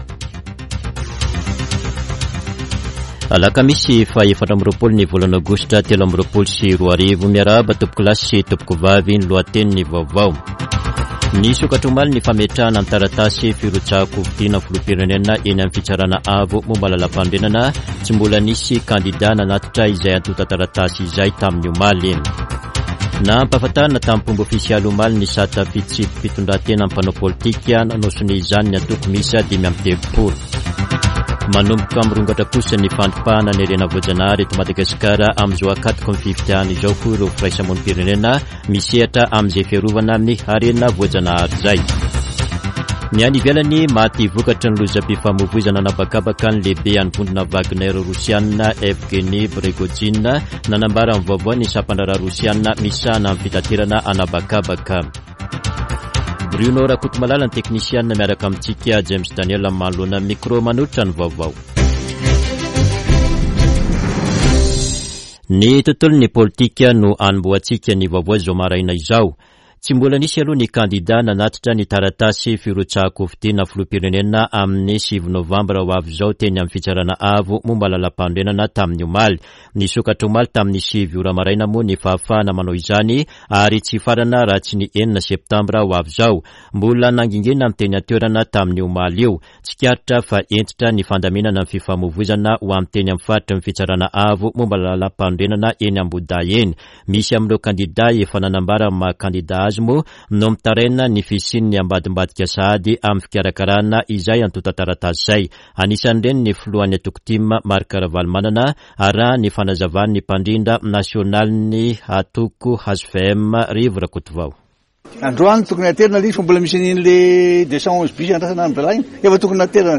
[Vaovao maraina] Alakamisy 24 aogositra 2023